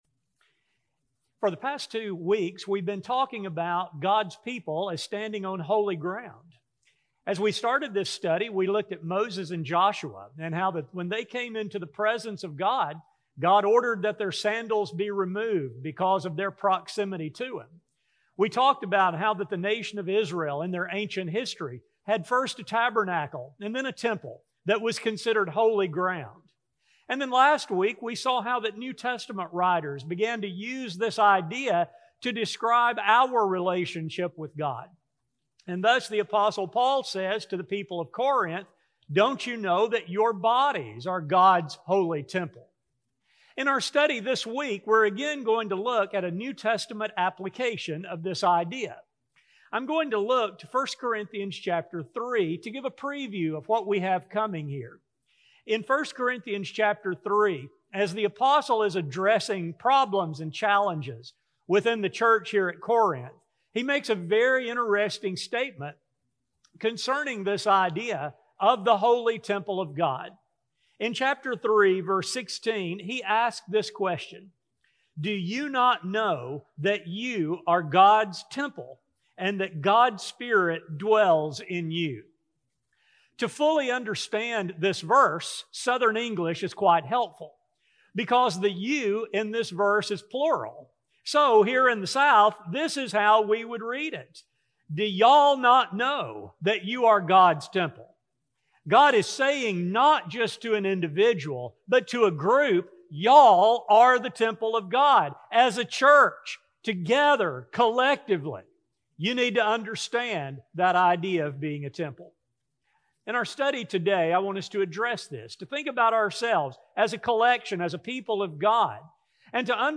A sermon recording